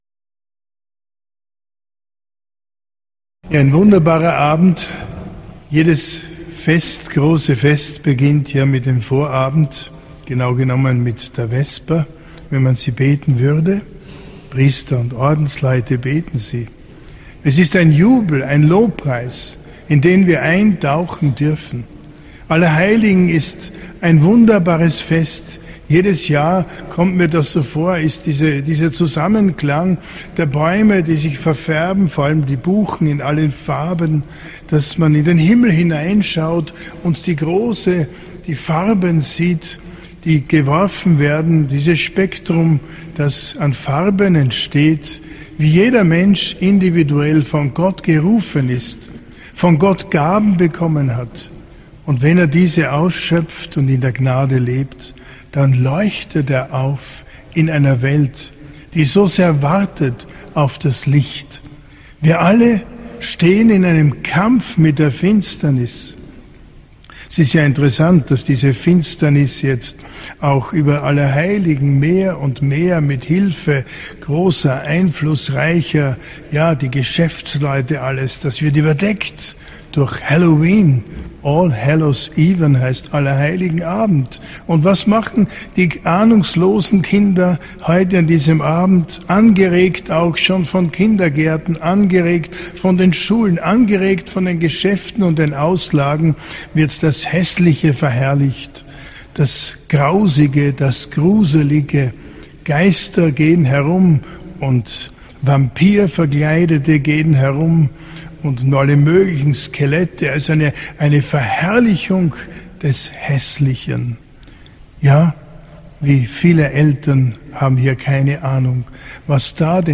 Verherrlichung des Häßlichen ~ Katholische Predigten & Vorträge Podcast